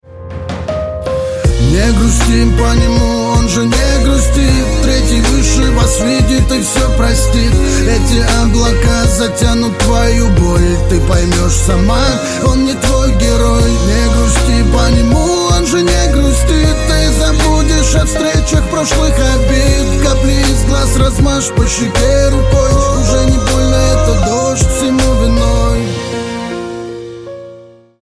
Грустные Рингтоны
Рэп Хип-Хоп Рингтоны